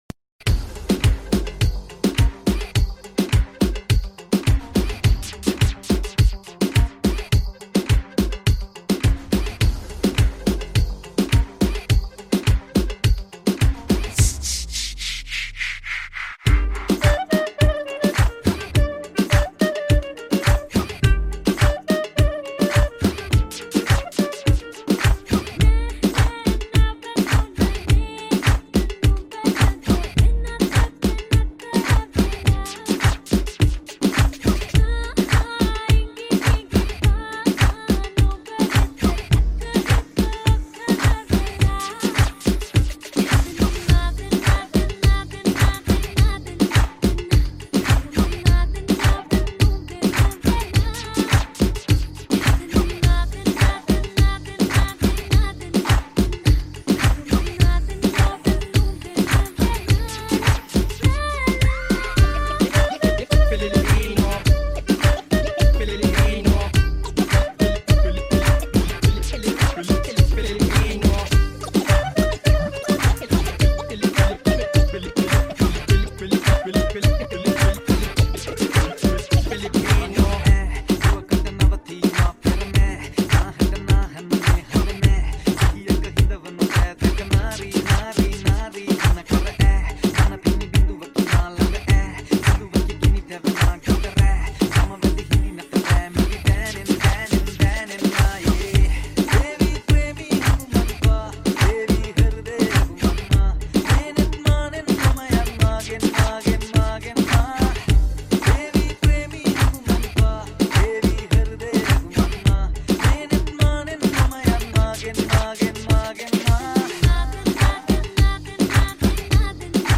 High quality Sri Lankan remix MP3 (2.9).